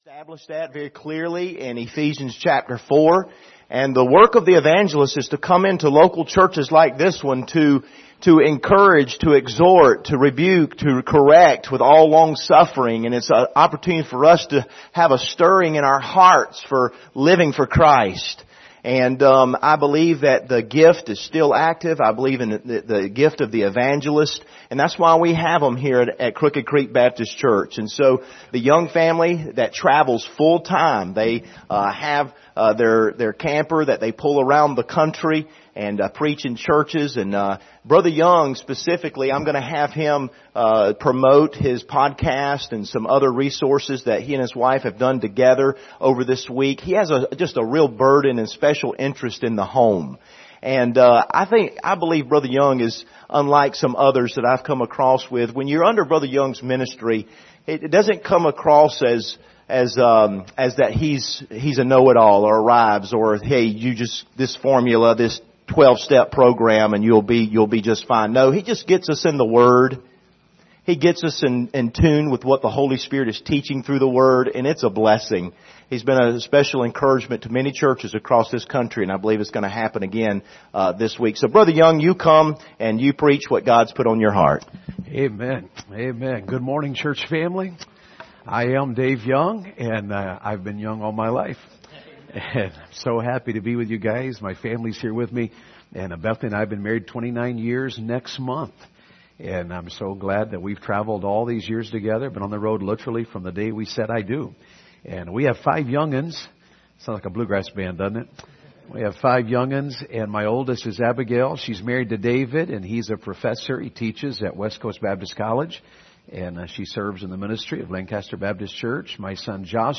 2022 Family Revival Passage: Genesis 1:26-31 Service Type: Sunday Morning Topics